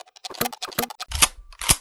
shotgun_reload.wav